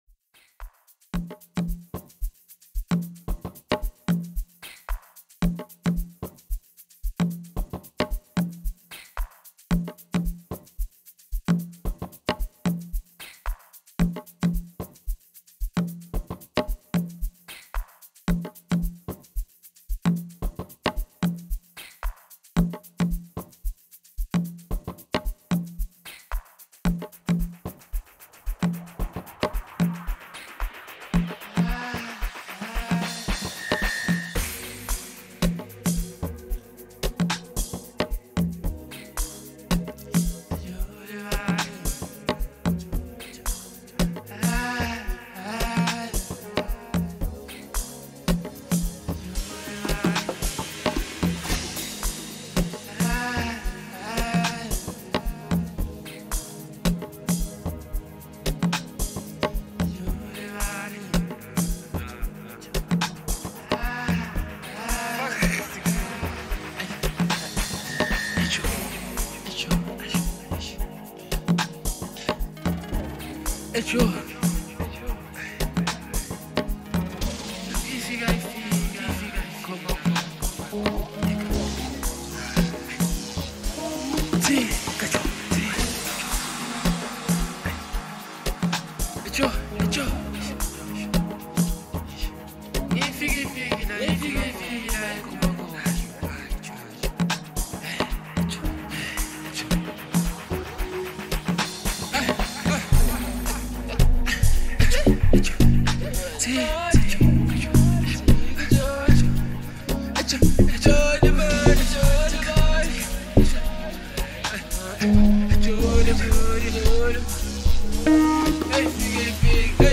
” a new Amapiano song